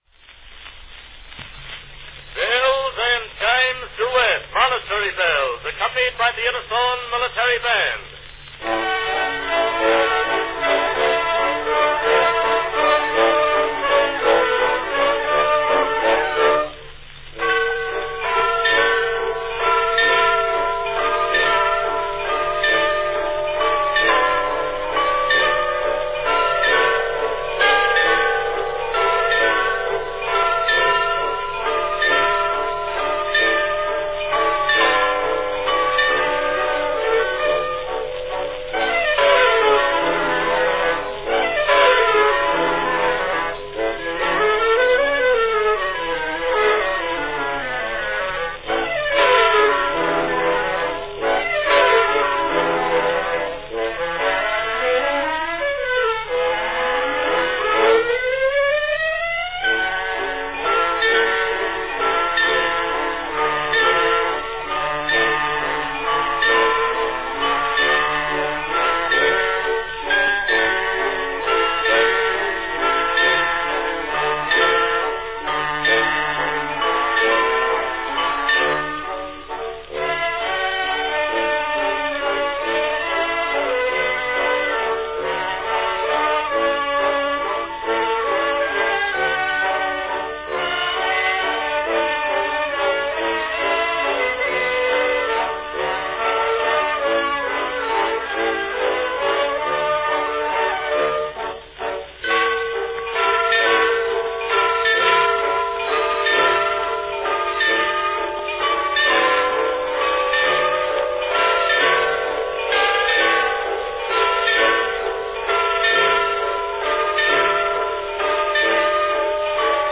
An uncommon recording of a bells and chimes duet
Category Bells & chimes duet
chimes
bells